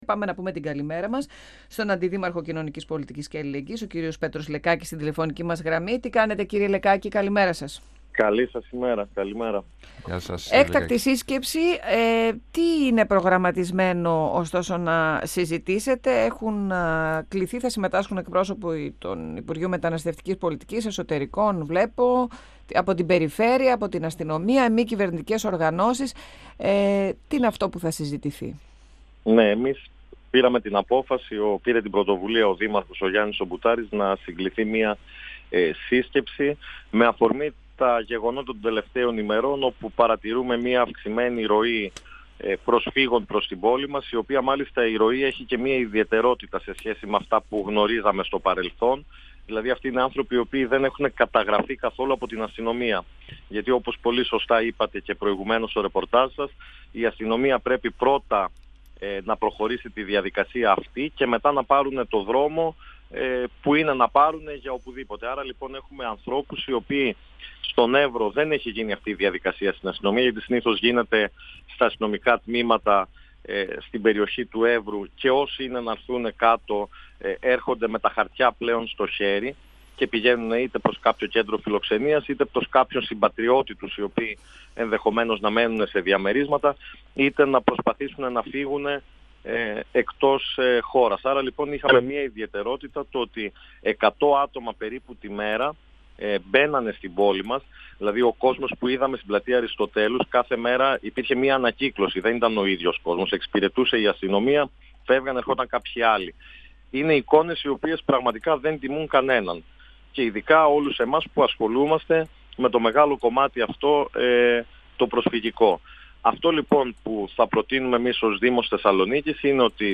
Ο αντιδήμαρχος Κοινωνικής Πολιτικής Πέτρος Λεκάκης στον 102FM του Ρ.Σ.Μ. της ΕΡΤ3